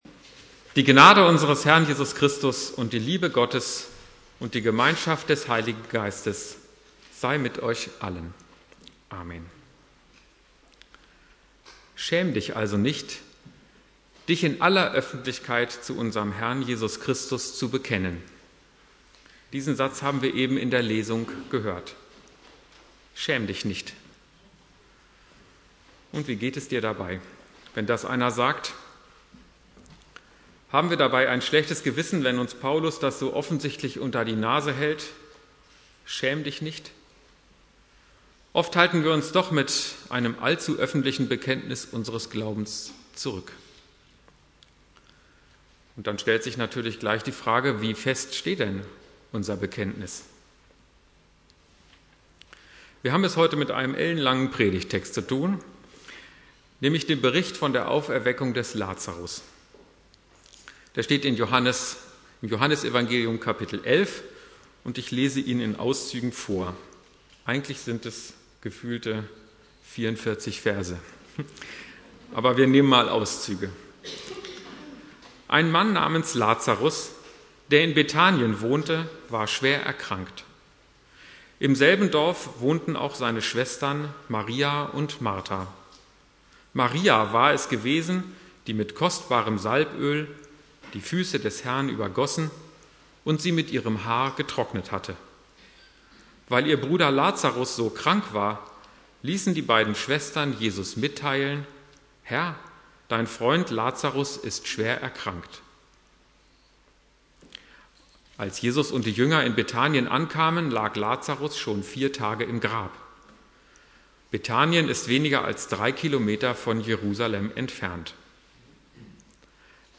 Die Auferweckung des Lazarus Inhalt der Predigt: Die Geschichte aus der Sicht Martas - Komm zu Jesus und lebe!